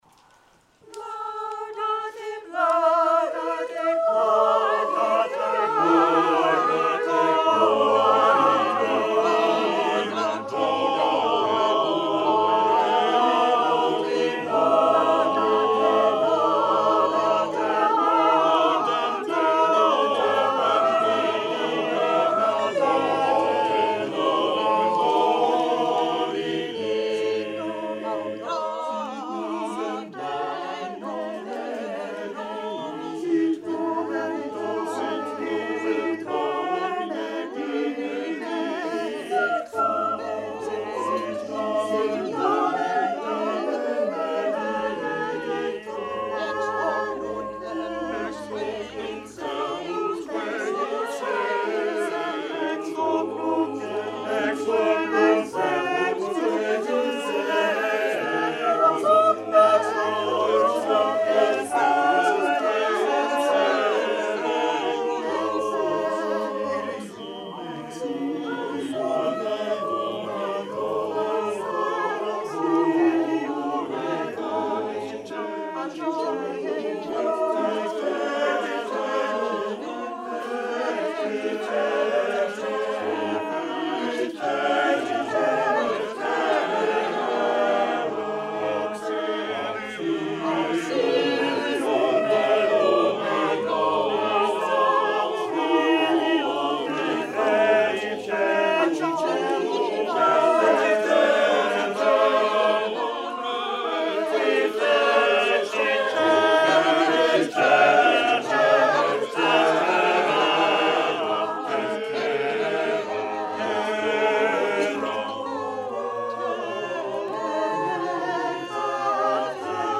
The Renaissance Street Singers' 45th-Anniversary Loft Concert, 2018